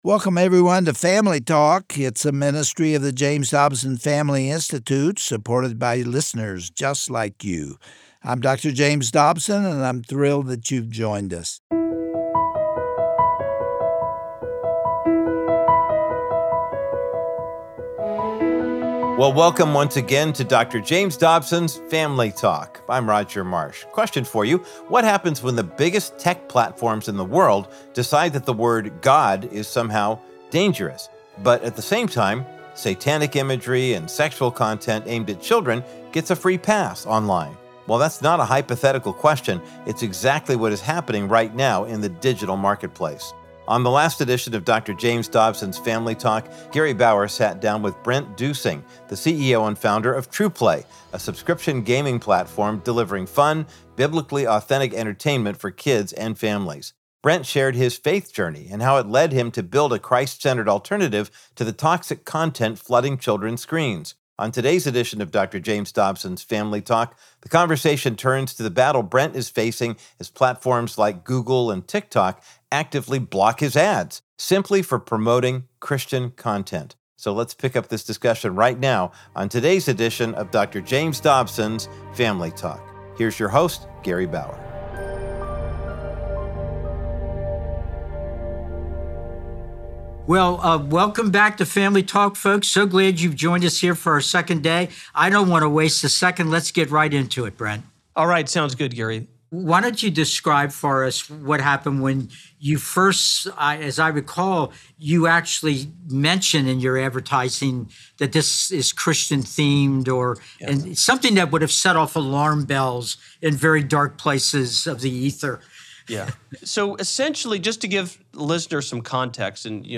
Host Gary Bauer